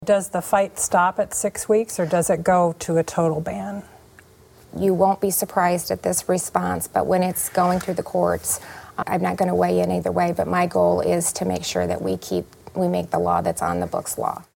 abortion and carbon pipelines during their debate last night on Iowa PBS.